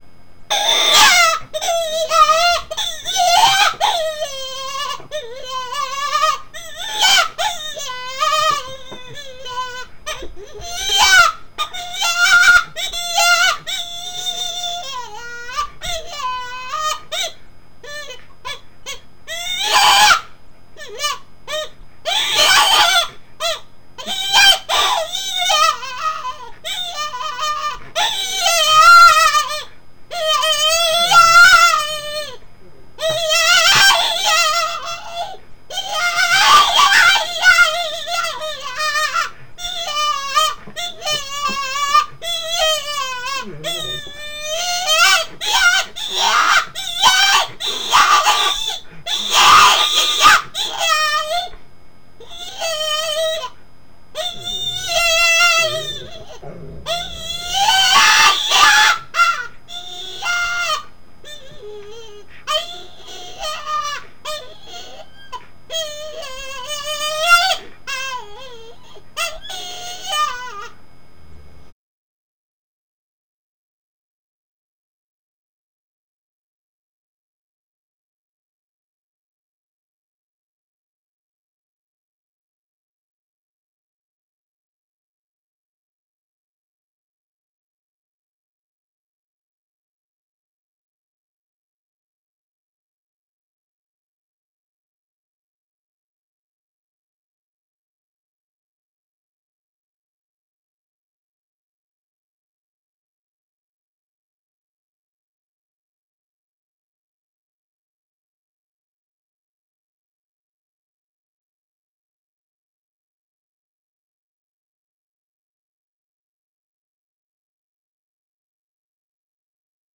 This is just a rough recording made to demonstrate my Excruciator closed-reed call.
There are segments of silence in there, too, so it isn't over until it's over hehe.